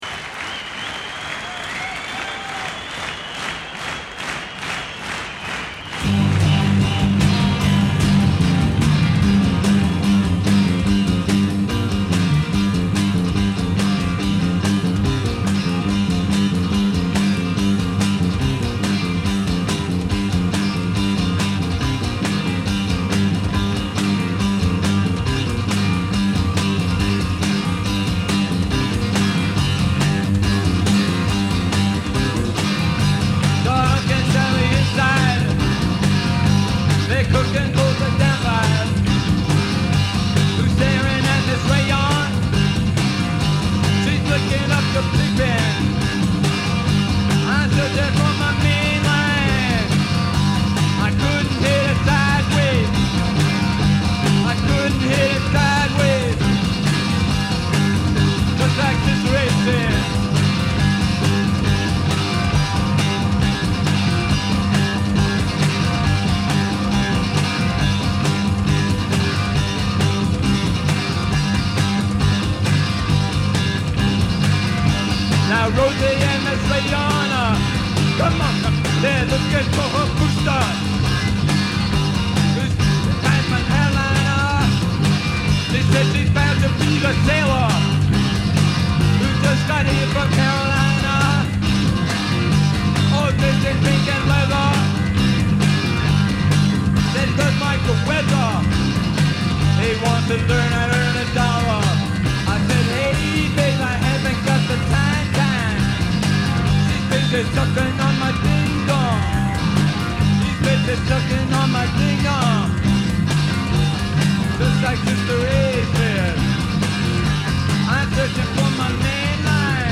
vocals, gtr
drms
keyboards